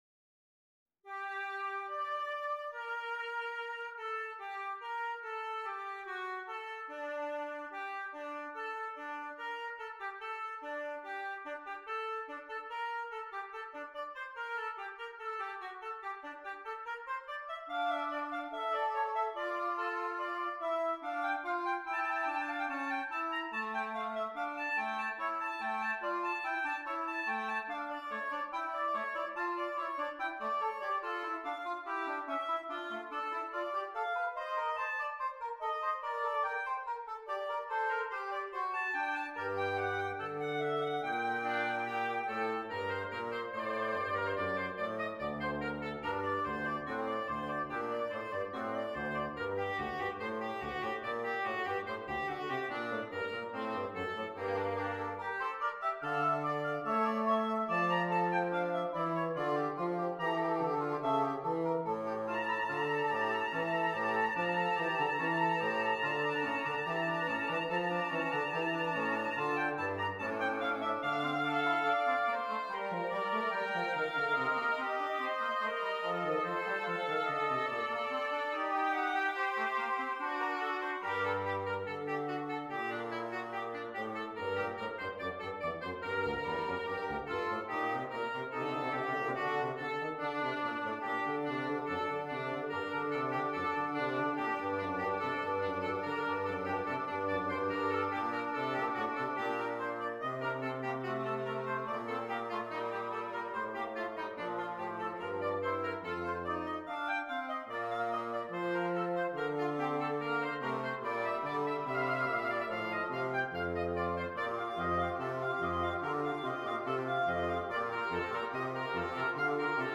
Double Reed Ensemble